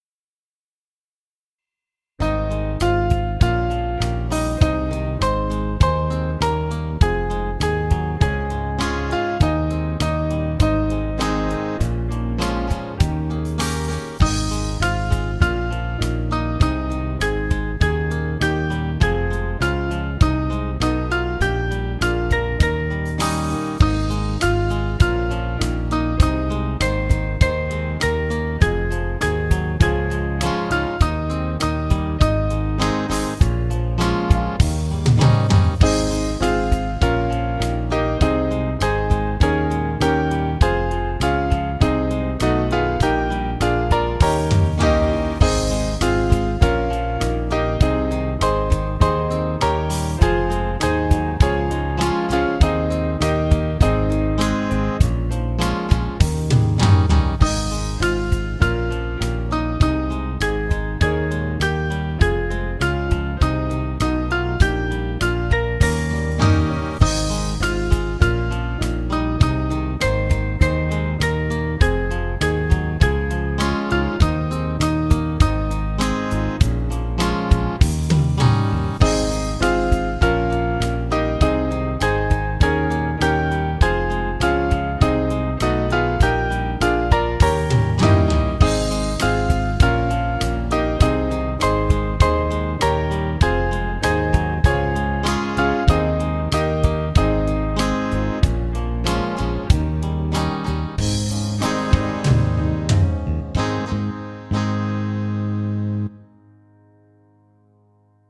Small Band
(CM)   4/Ab 477.2kb